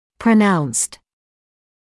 [prə’naunst][прэ’наунст]очень выраженный; заметный, ощутимый